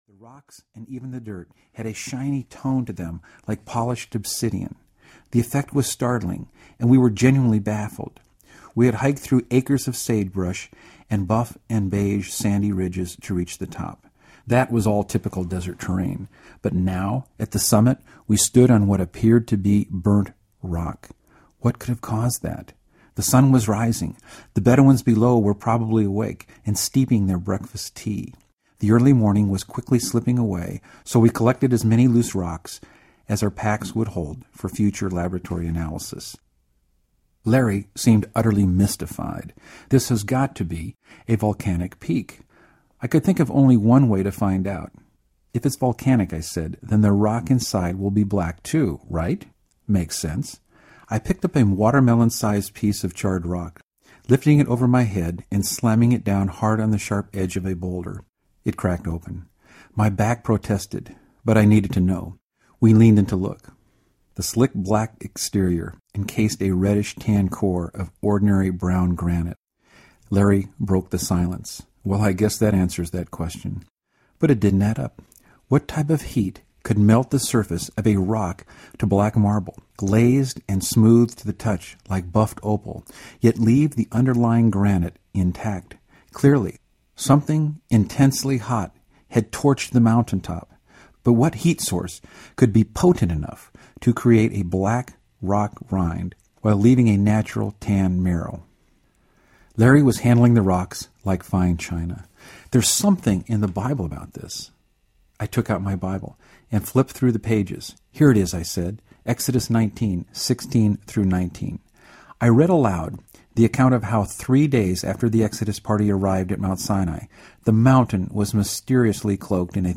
Relic Quest Audiobook
Narrator